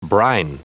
Prononciation du mot brine en anglais (fichier audio)